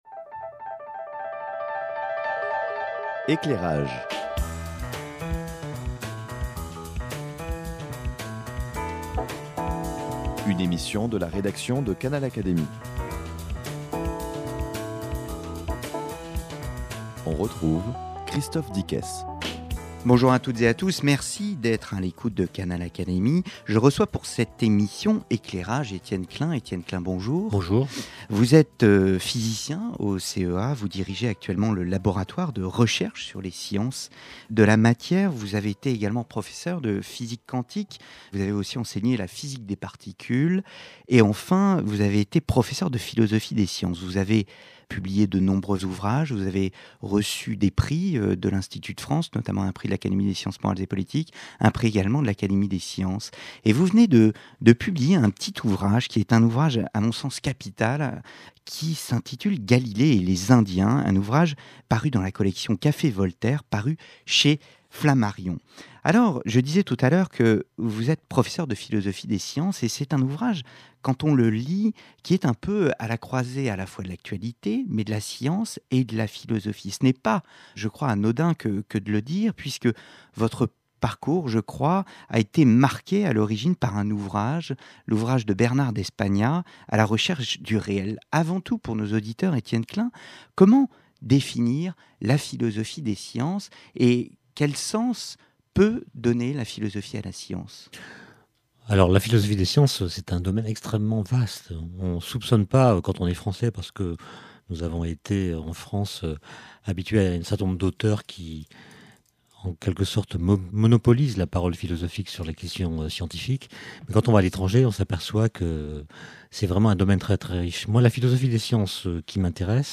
Il est reçu à Canal Académie